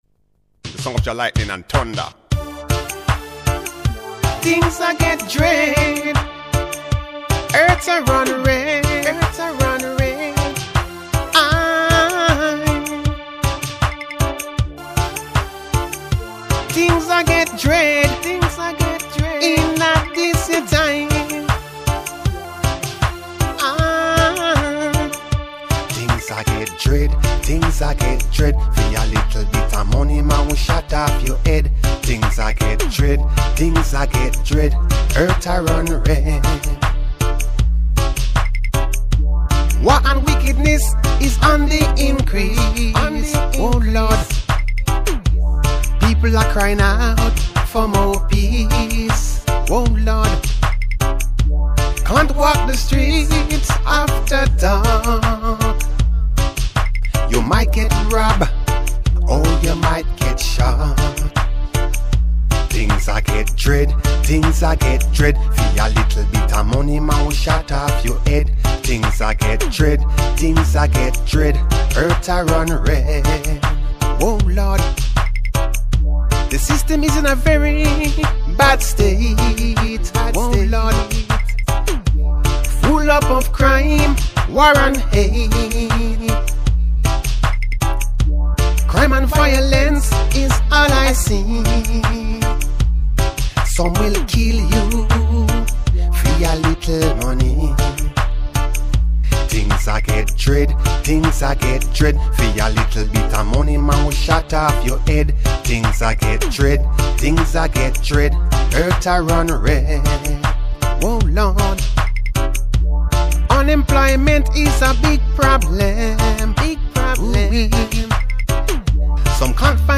DUB PLATE STYLE